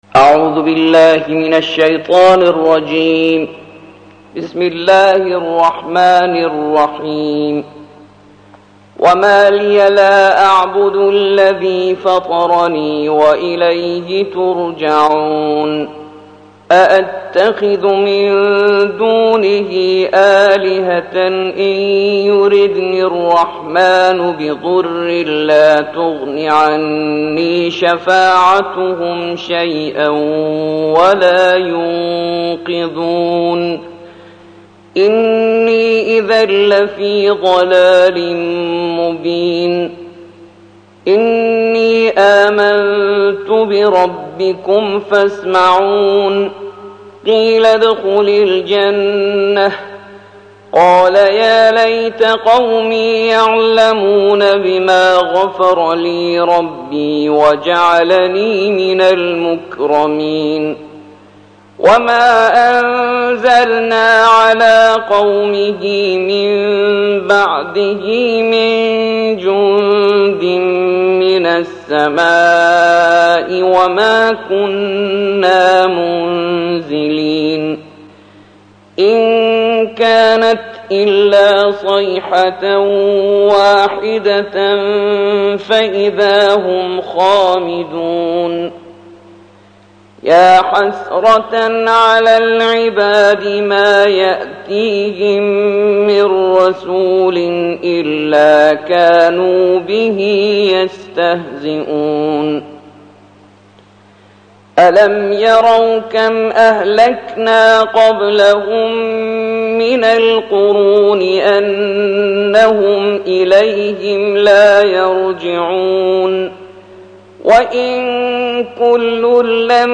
الجزء الثالث والعشرون / القارئ